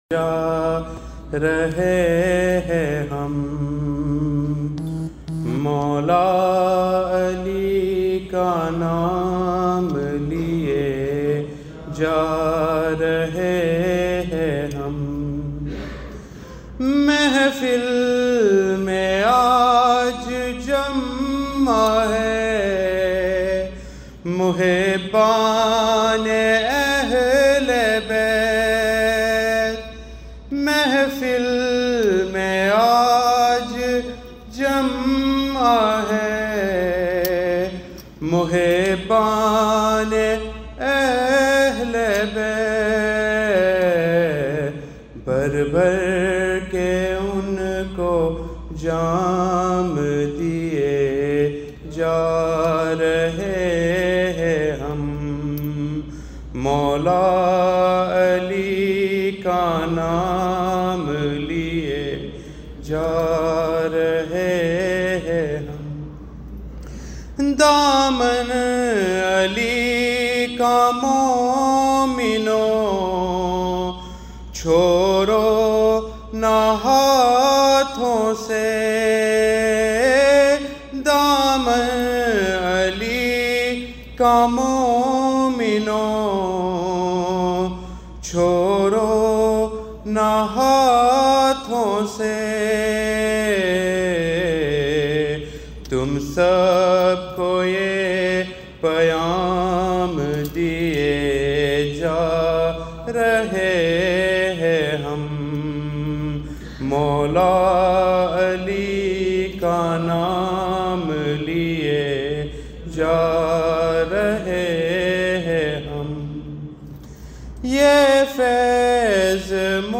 Non Saff Classic Munajaats